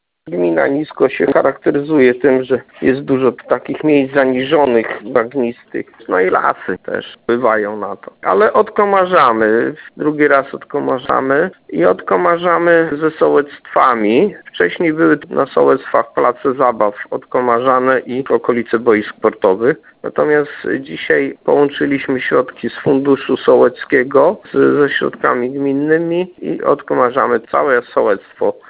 Mówi burmistrz Niska